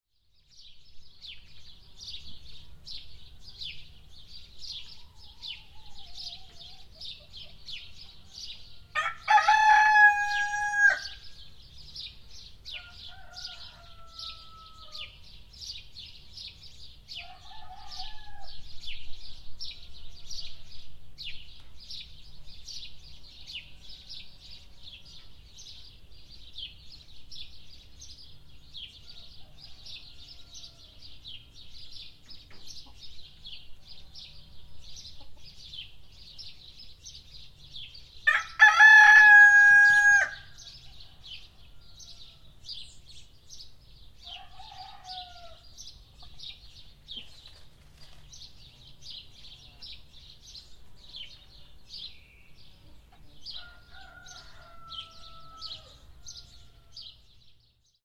دانلود صدای خروس محلی همراه با صدای گنجشکان در یک روز گرم تابستانی از ساعد نیوز با لینک مستقیم و کیفیت بالا
جلوه های صوتی